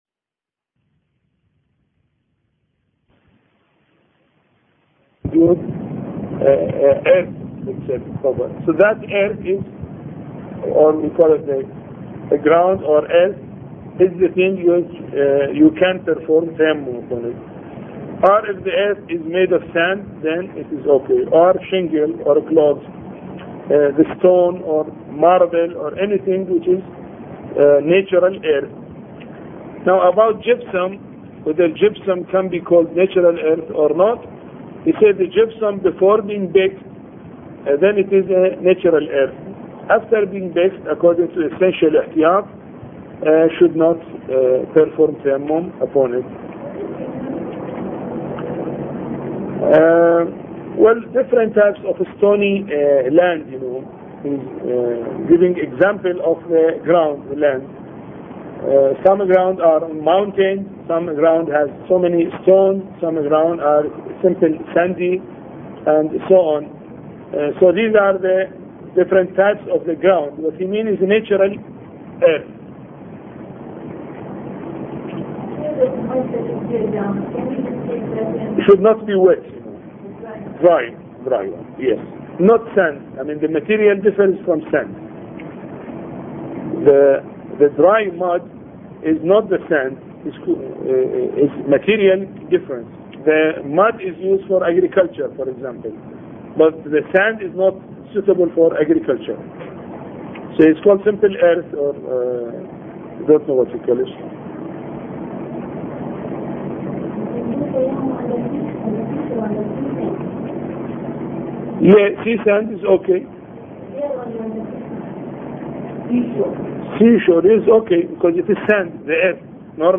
A Course on Fiqh Lecture 12